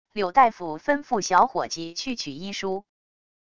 柳大夫吩咐小伙计去取医书wav音频生成系统WAV Audio Player